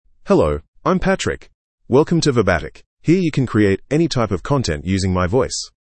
MaleEnglish (Australia)
Patrick is a male AI voice for English (Australia).
Voice sample
Male
Patrick delivers clear pronunciation with authentic Australia English intonation, making your content sound professionally produced.